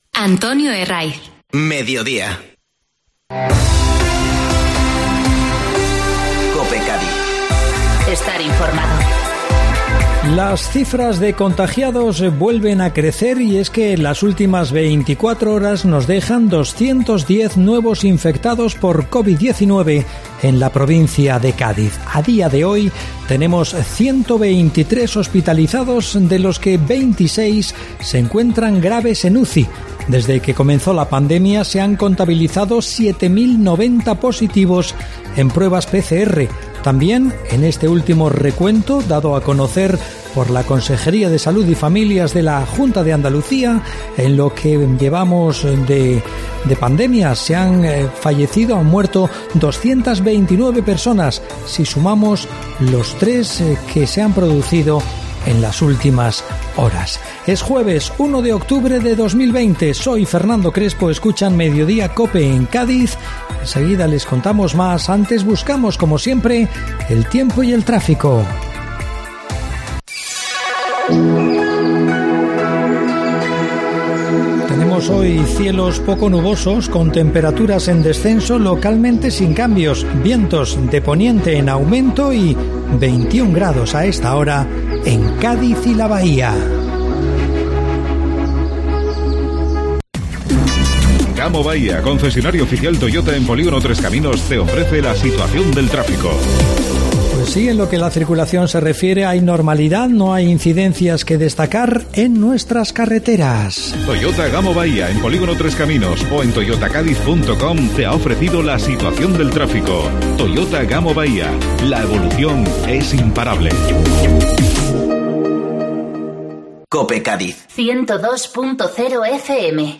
Informativo Mediodía COPE Cádiz (1-10-20)